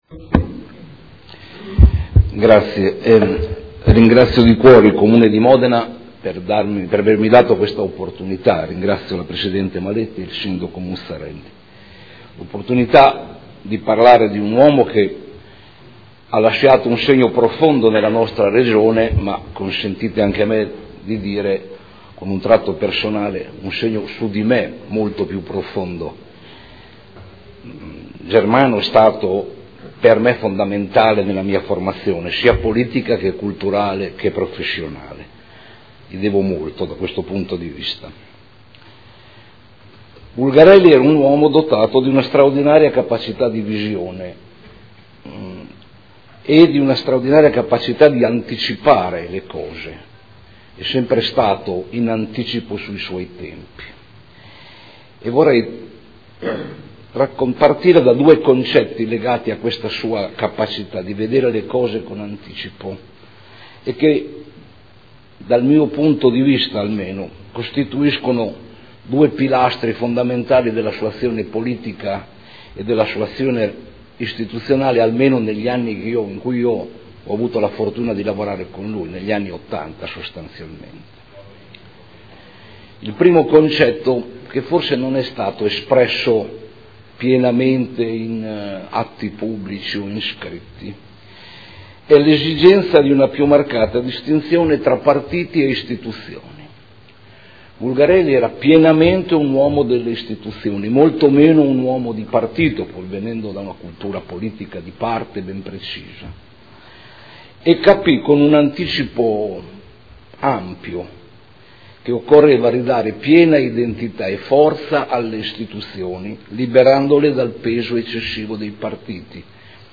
Seduta del 2 luglio. Commemorazione del Sindaco Germano Bulgarelli ad un anno dalla scomparsa. Interviene Giulio Santagata – Germano Bulgarelli e il riformismo possibile